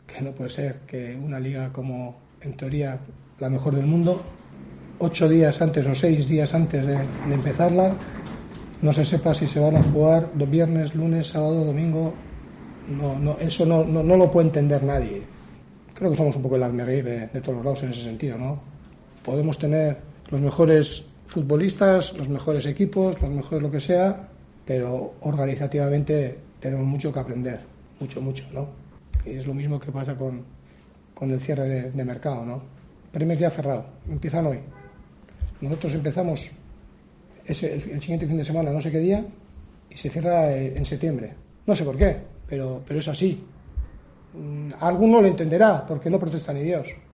AUDIO: El técnico del Eibar se ha mostrado especialmente molesto al hablar sobre los horarios de los encuentros y la guerra entre LaLiga y la RFEF.